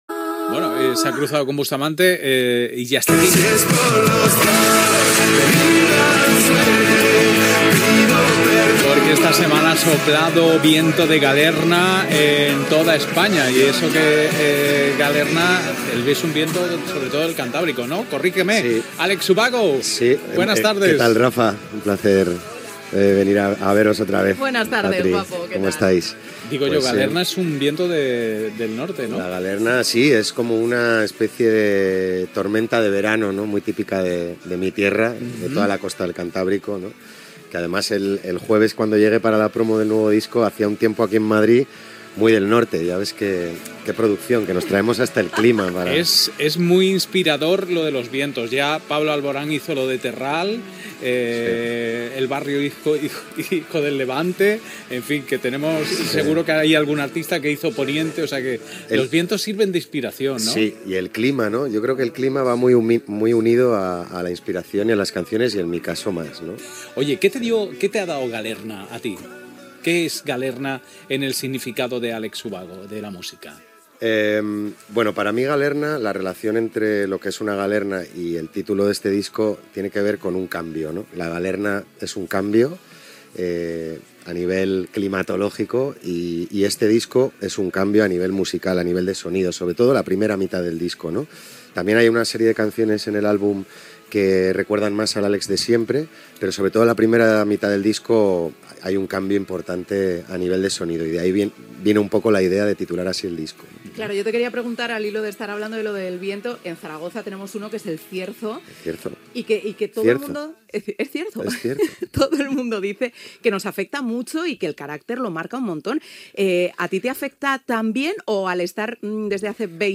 Entrevista al cantant Alex Ubago que presenta el disc "Galerna"